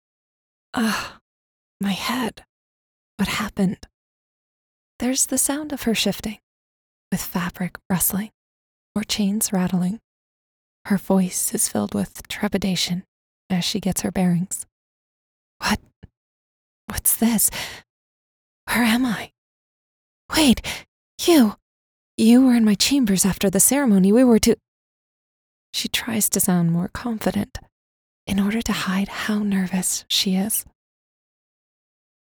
Narration
Rode NT 1, SSL2 Interface, Reaper, Custom Built Studio PC, Sound Booth, Live Direction
Mezzo-SopranoSoprano